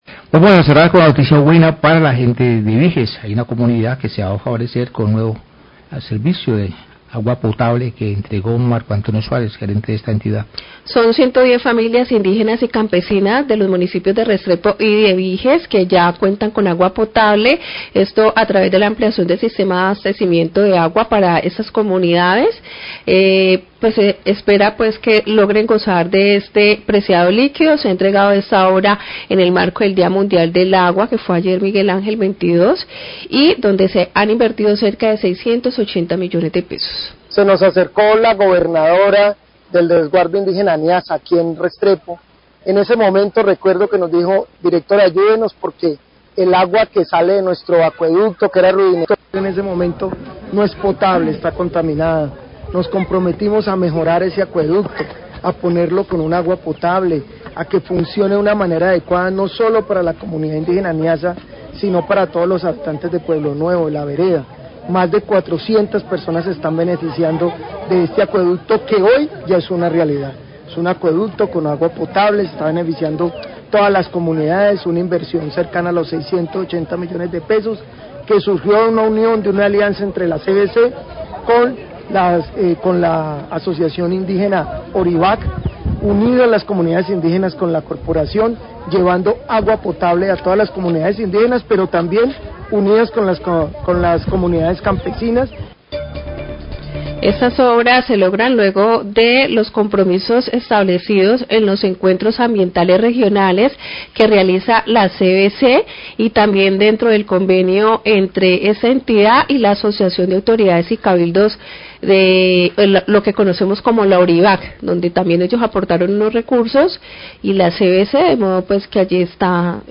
Radio
El director general de la CVC, Marco Antonio Suárez, habla d ela entrega de obras de ampliación del sistema de abastecimiento de agua realizado por la orporación y con el cual 110 familias de comunidades indígenas y campesinas de los municipios de Vijes y Restrepo podrán consumir agua potable.